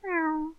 Animals, beasts monsters » meow
描述：Intermediate meow. (no animals were harmed this sound was performed and recorded by myself).
标签： meowing animal cats cat kitty feline meow
声道立体声